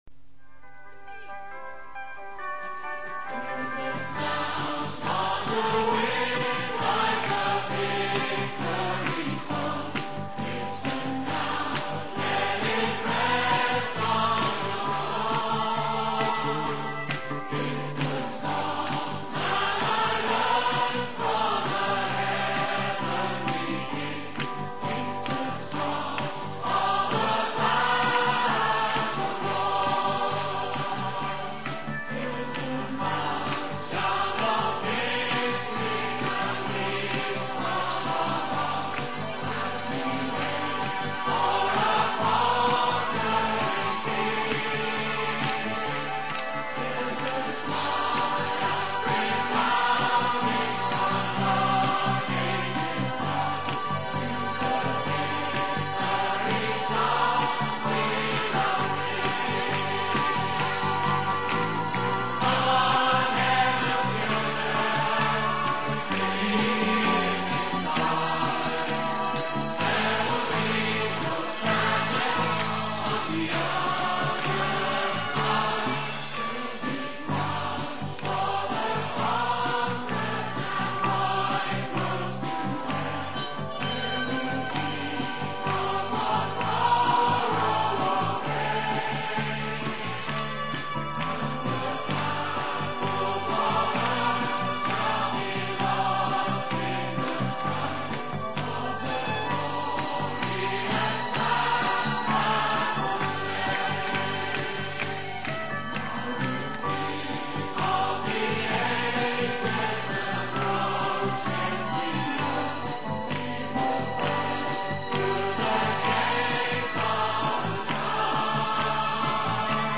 In this sermon, the speaker reflects on the value of time and the importance of prayer.